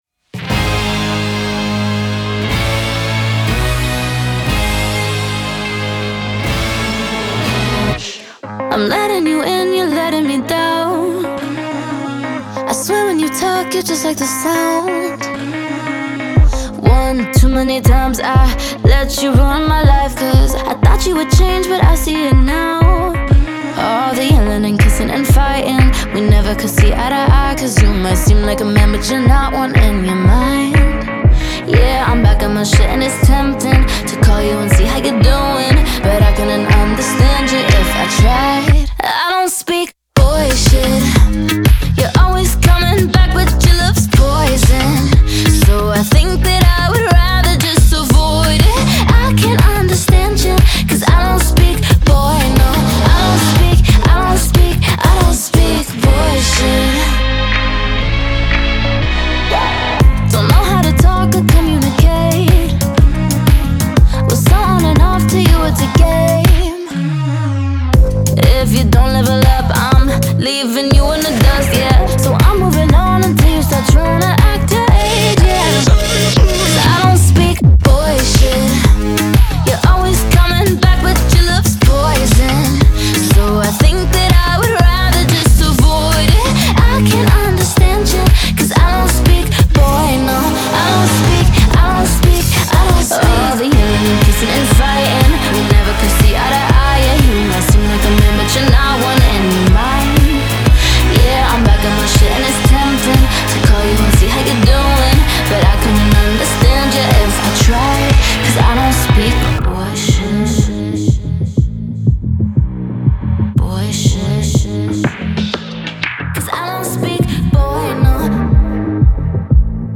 поп-песня
которая сочетает в себе элементы R&B и поп-музыки.